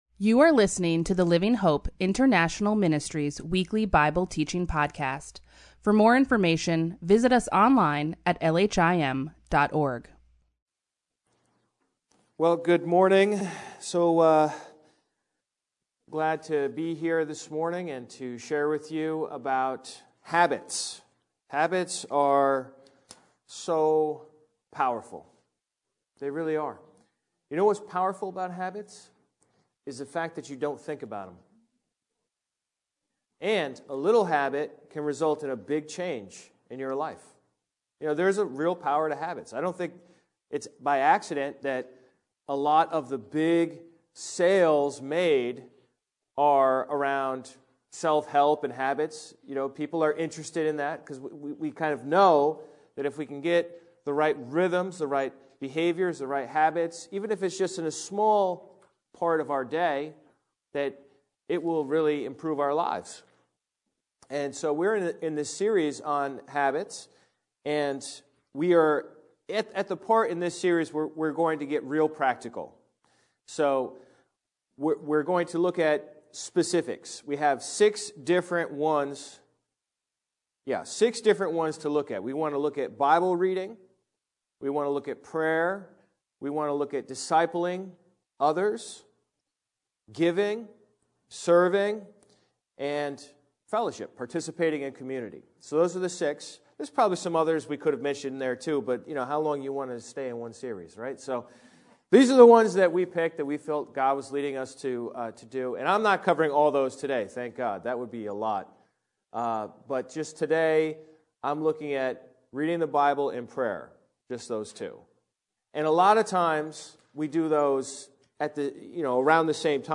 Weekly Bible Teaching